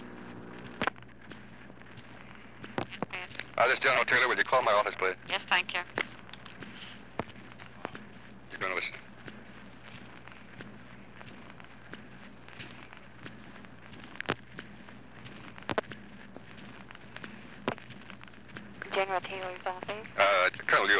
Conversation with Dean Rusk